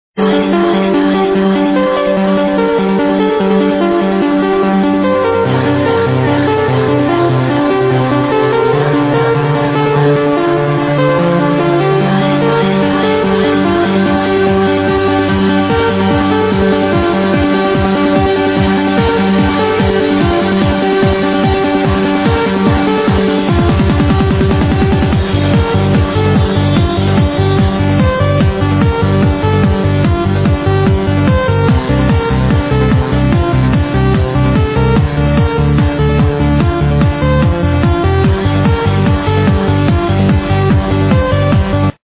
The main melody is very beautiful I must say
Powered by: Trance Music & vBulletin Forums